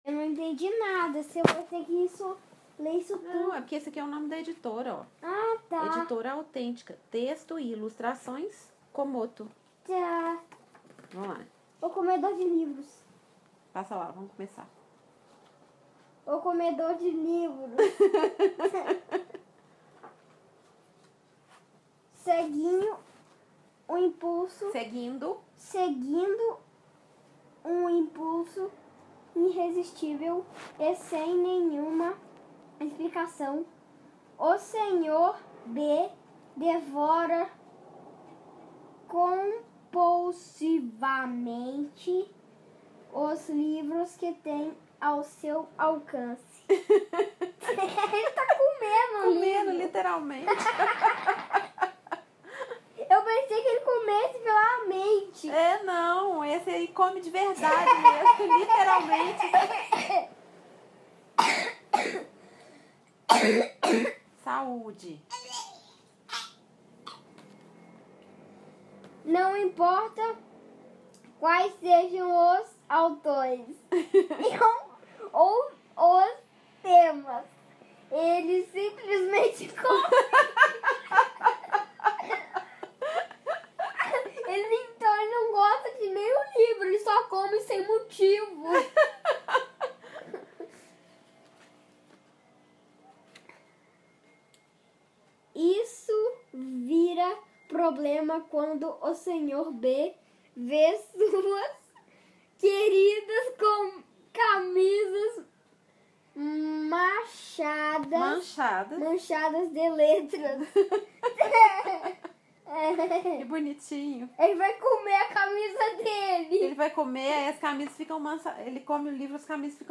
lê "O comedor de livros"